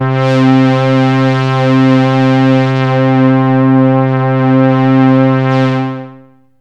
AMBIENT ATMOSPHERES-4 0008.wav